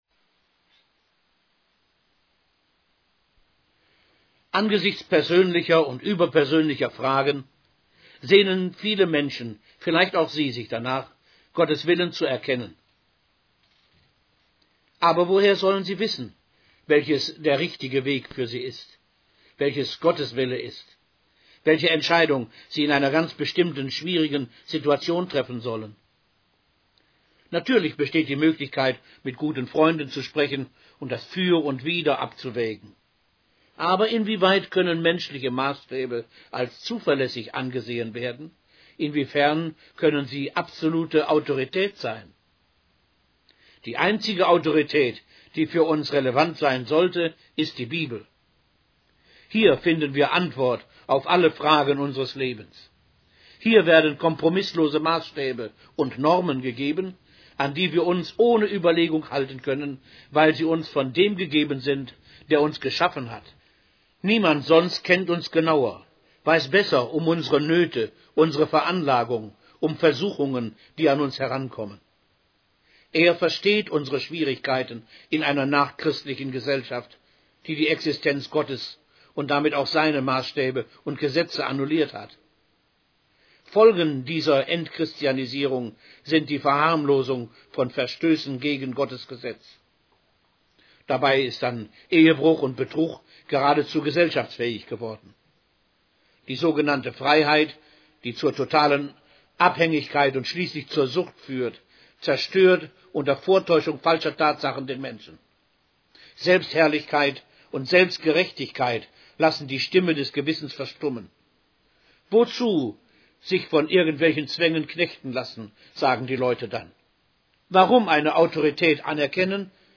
Kostenlos: MP3-Predigten